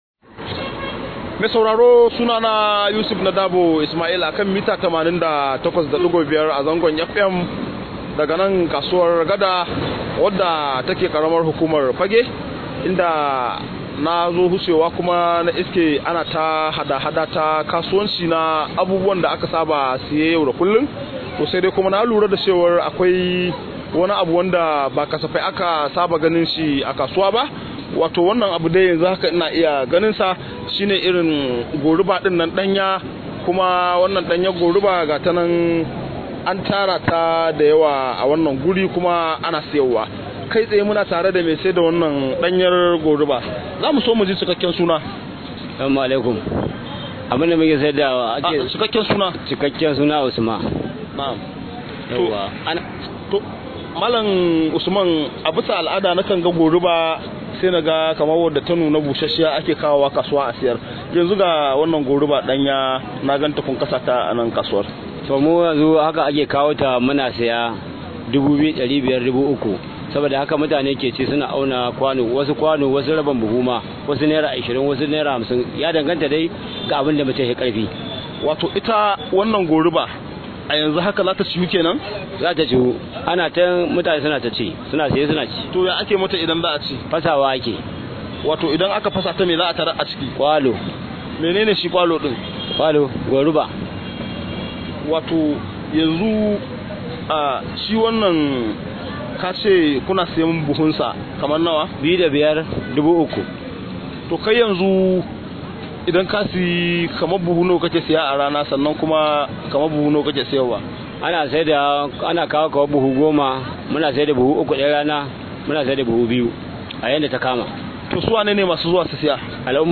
Rahoto: Yadda Goriba ta zama abinci a Kano